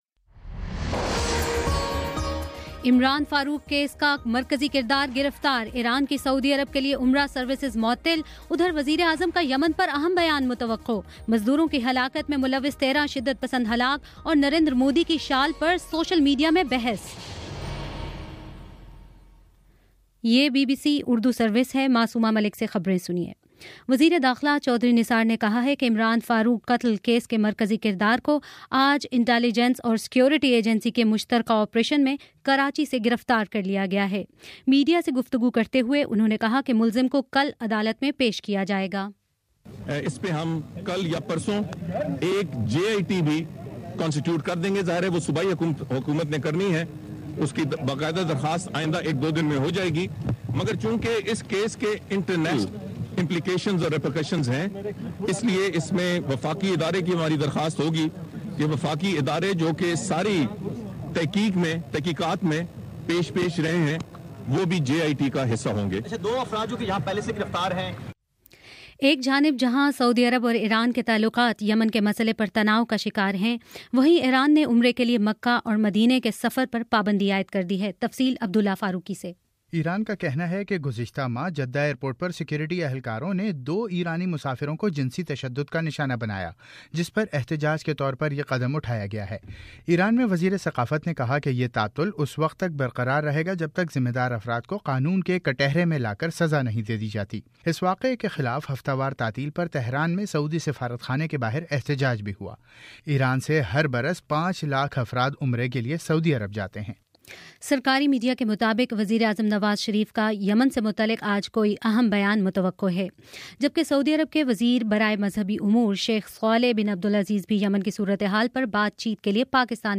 اپریل 13: شام چھ بجے کا نیوز بُلیٹن